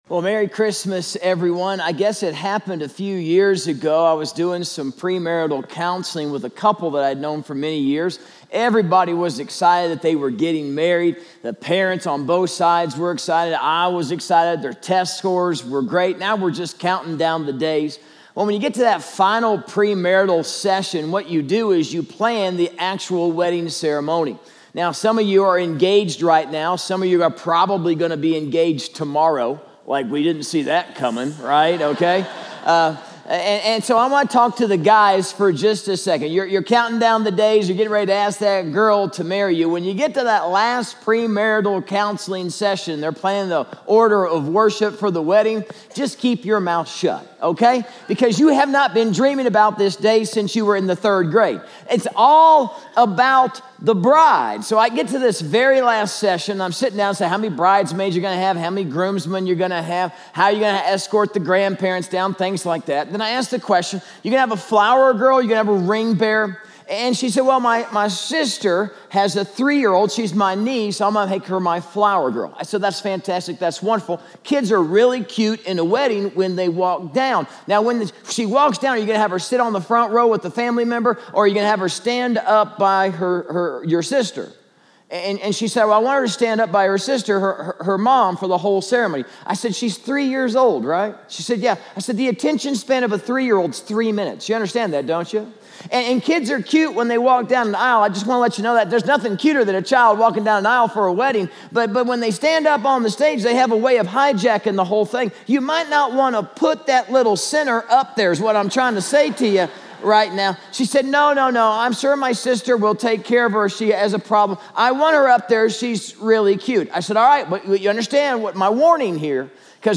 Sermons by Sagebrush Church